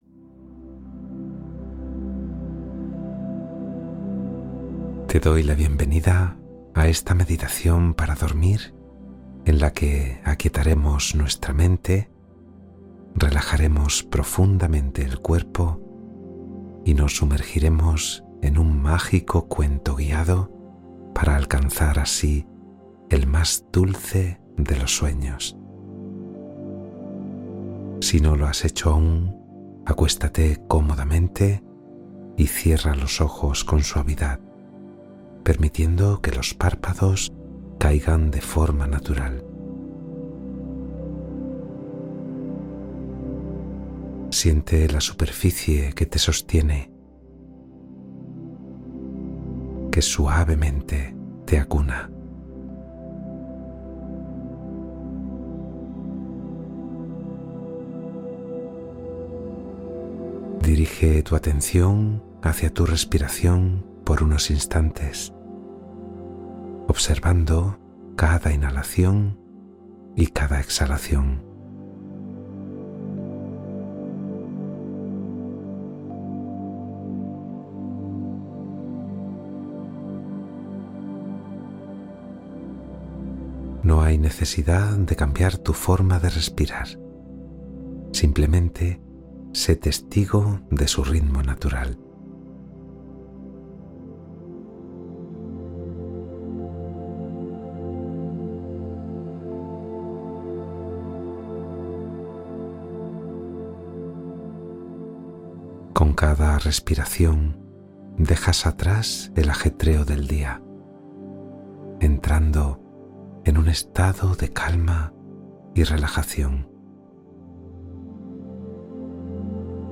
Relajación y Cuento para un Sueño Reparador